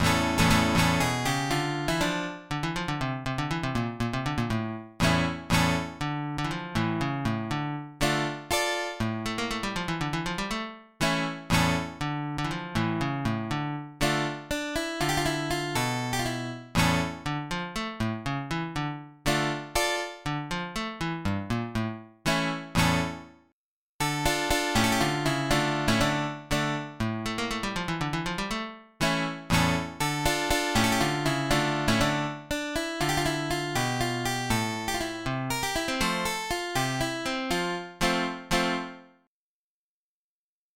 Brani da non perdere (Flamenco):
alba_op37_Viva-Jerez_Nueva-Petenera.mid.mp3